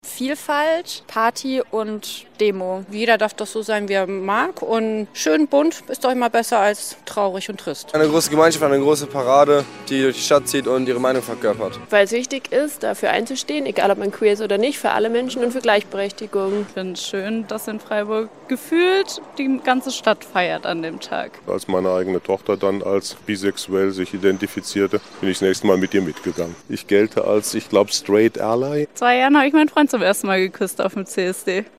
Freiburgerinnen und Freiburger darüber, was sie mit dem CSD verbinden :